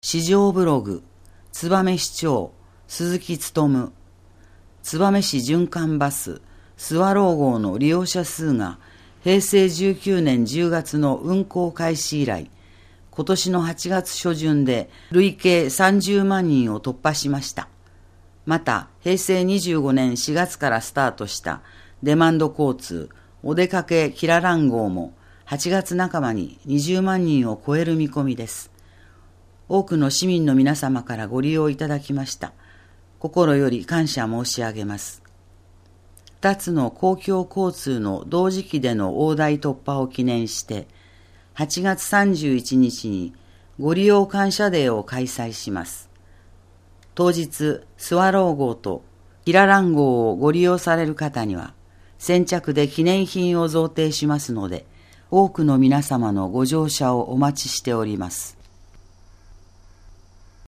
声の広報つばめ2018年8月15日号